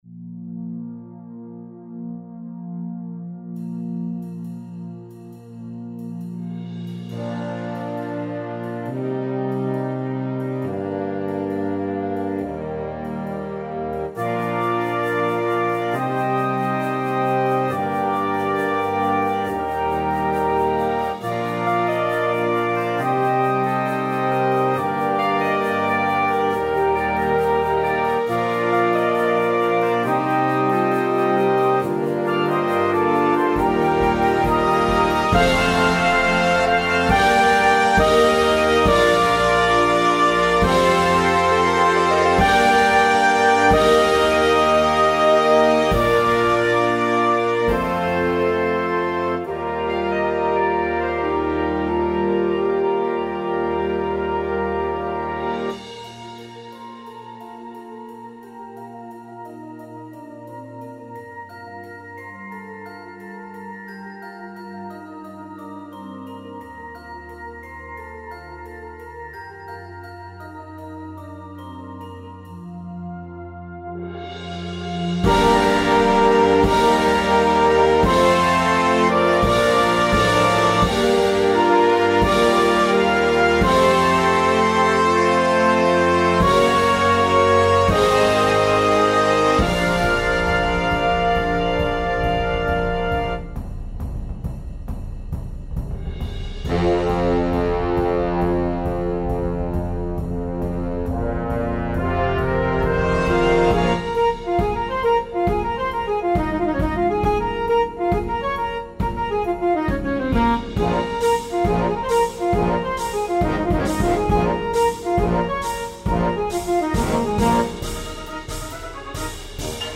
is an epic marching band show inspired by Norse myths